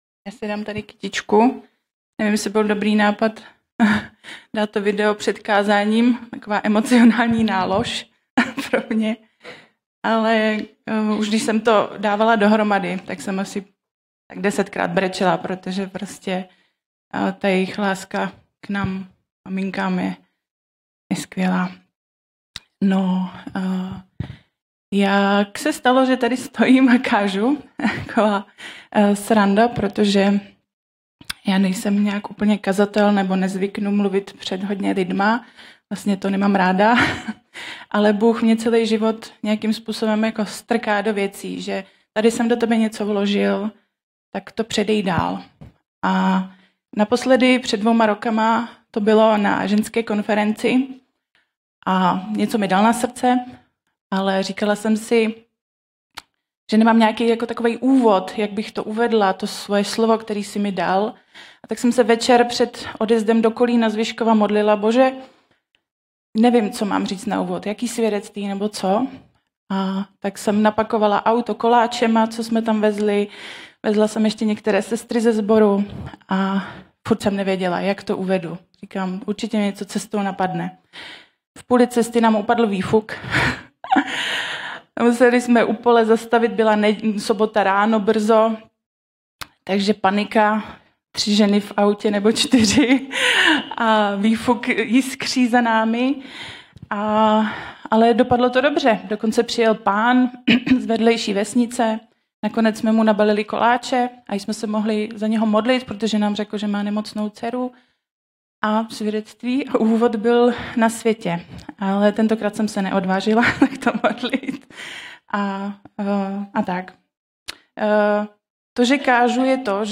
Pro váš užitek zveřejňujeme výběr z nahrávek biblických kázání Apoštolské církve ve Vyškově.